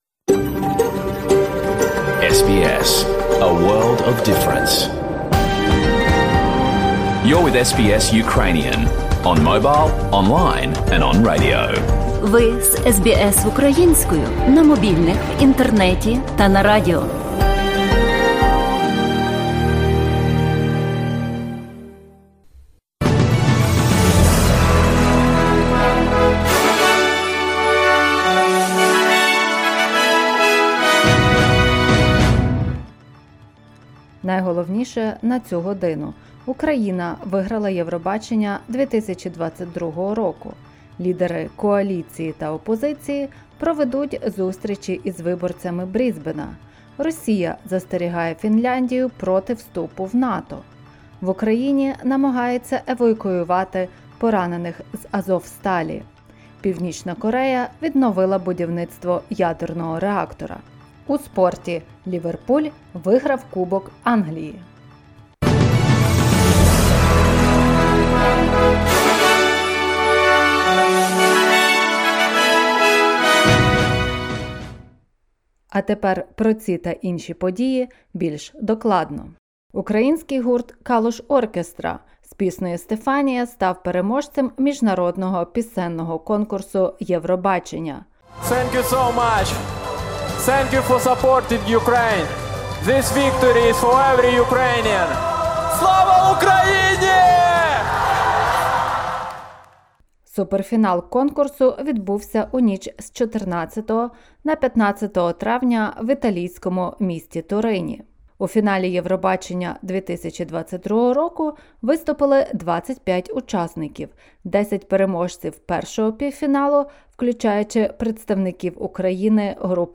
In this bulletin: Prime Minister Scott Morrison to announce a housing and superannuation policy. Russian President Vladimir Putin warns that Finland joining NATO would be a mistake. Ukraine has won this year's Eurovision Song Contest.